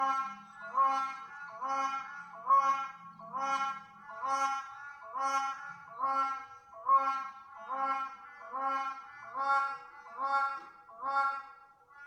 Unison Call | A duet performed by a pair, to strengthen their bond and protect their territory.
Black-Crowned-Crane-Unison.mp3